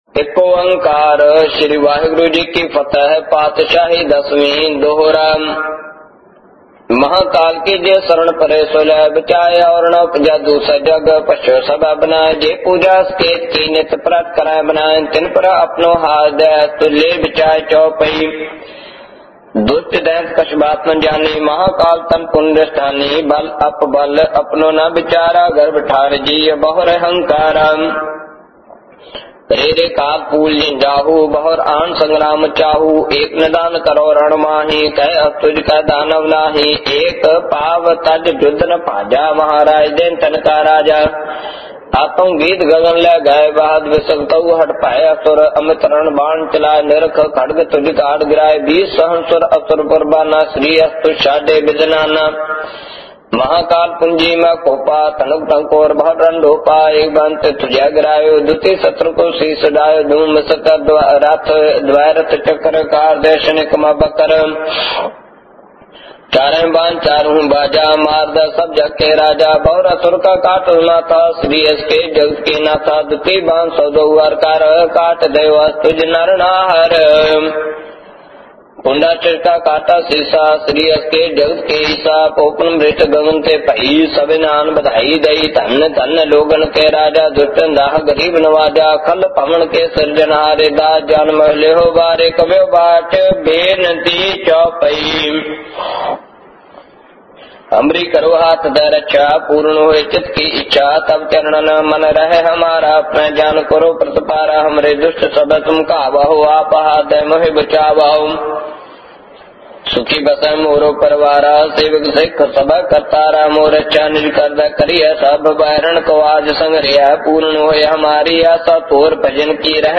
Genre: -Gurbani Ucharan